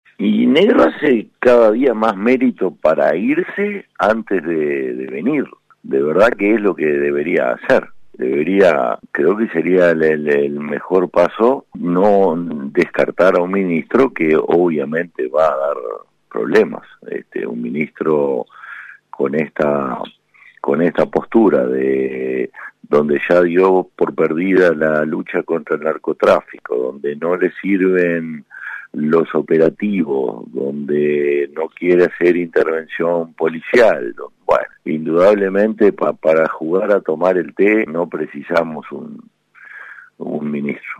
Escuche a Sergio Botana aquí:
El senador del Partido Nacional, Sergio Botana, se refirió en entrevista con 970 Noticias, a las declaraciones del ministro del Interior designado por Yamandú Orsi, Carlos Negro, quien reivindicó, en diálogo con Brecha, la gestión del exministro durante el gobierno del Frente Amplio, Eduardo Bonomi.